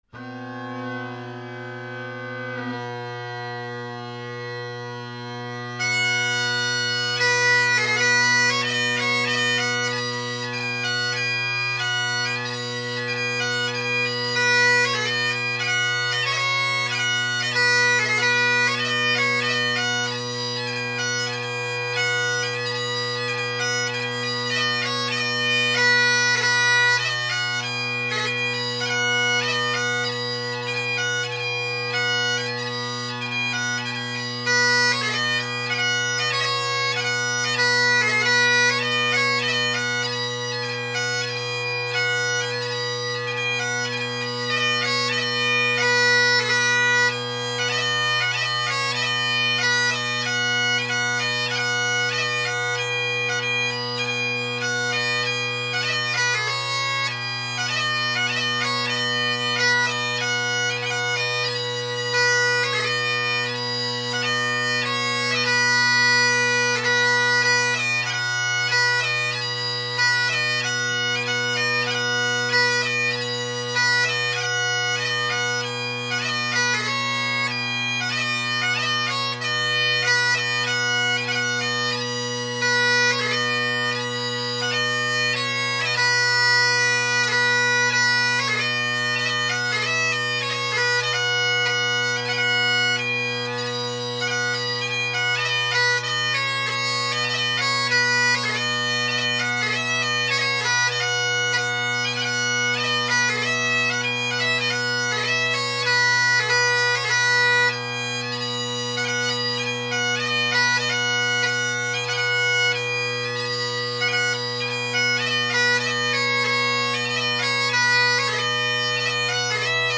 This chanter is louder than any other I’ve recorded, so pay attention to your speaker/headphone volume.
My Band’s 4/4 March Medley – facing away from the mic
The pipes played are my Colin Kyo bagpipe with Ezee tenors and short inverted Ezee bass.